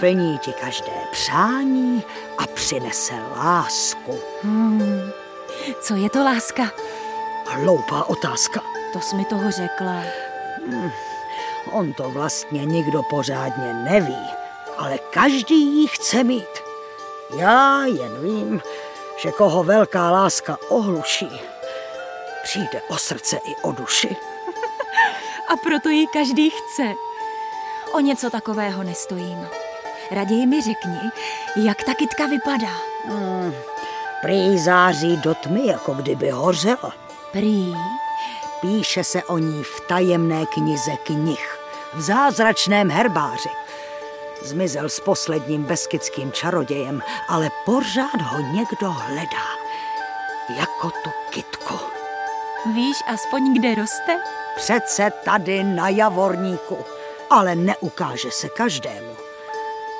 Audiobook
Read: Jaromír Meduna